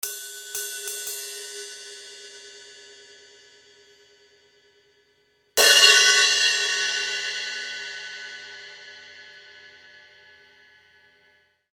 - und zwei Paiste 2000 Crashes in 16“,
Je mehr aber in den gehämmerten Bereich eingegriffen wird, desto heller/ spontaner/ agressiver und „synthetischer“ klingt das Becken, tendenziell findet, meiner Meinung nach, klanglich dann eine Annäherung an den Sound von Messingbecken statt.